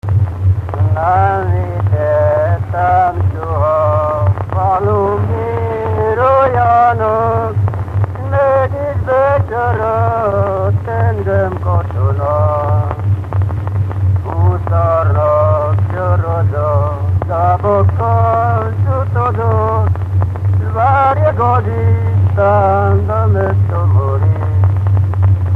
Erdély - Csík vm. - Csíkverebes
ének
Stílus: 8. Újszerű kisambitusú dallamok